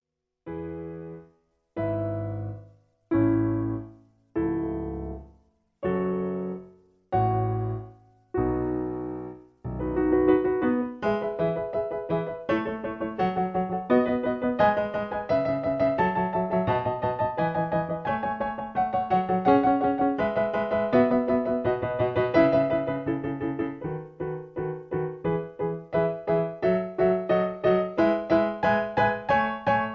In C sharp. Piano Accompaniment